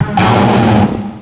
pokeemerald / sound / direct_sound_samples / cries / terrakion.aif